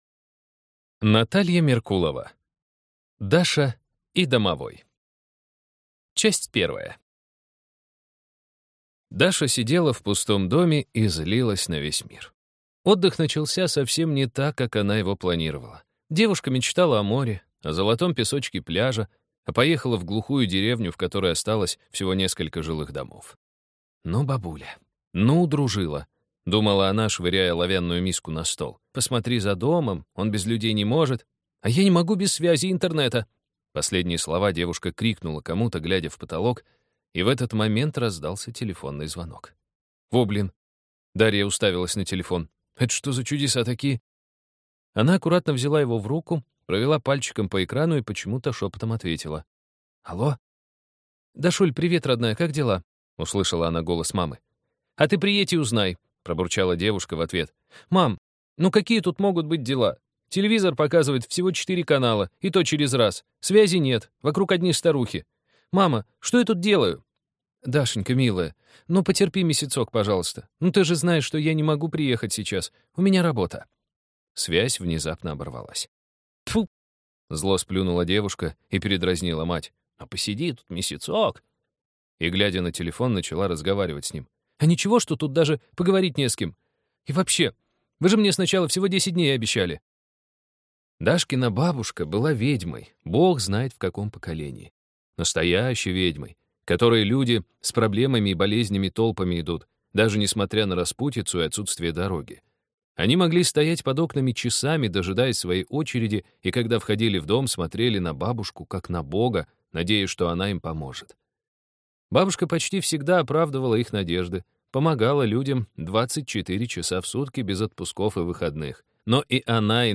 Аудиокнига Даша и Домовой | Библиотека аудиокниг
Прослушать и бесплатно скачать фрагмент аудиокниги